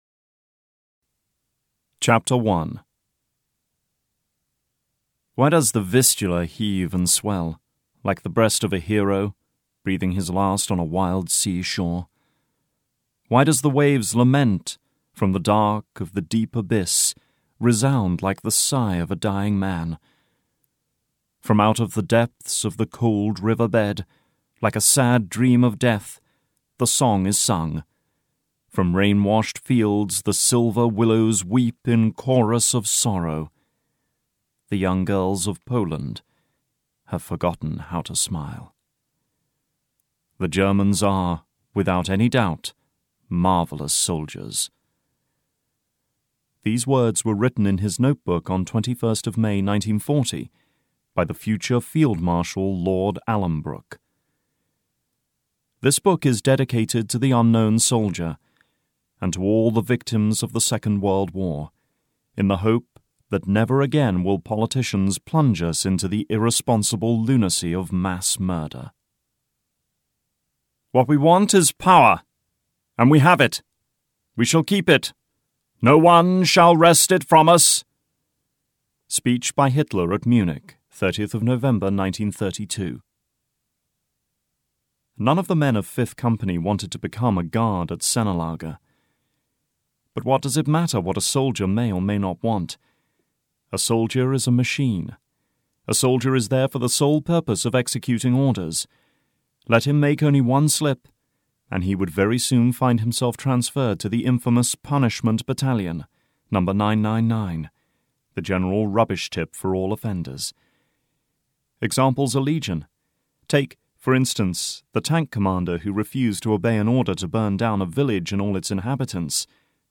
Reign of Hell (EN) audiokniha
Ukázka z knihy